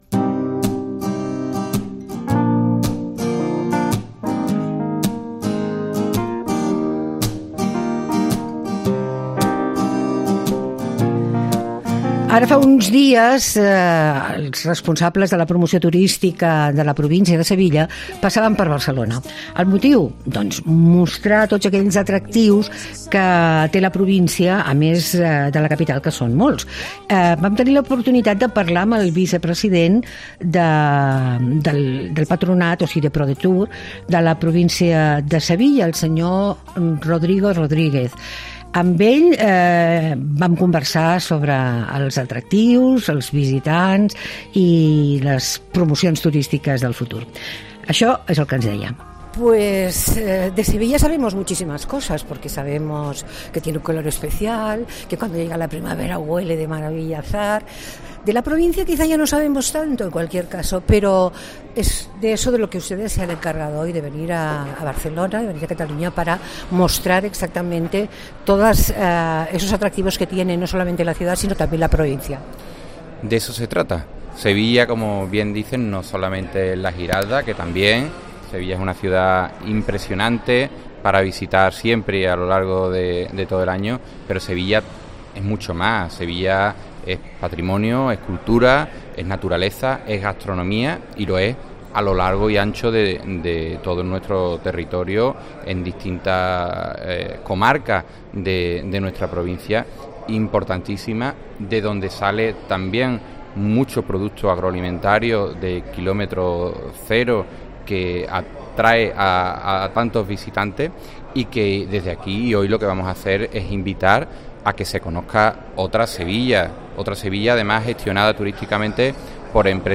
Todos los domingos hacemos una hora de radio pensada para aquellos que les gusta pasarlo bien en su tiempo de ocio ¿donde?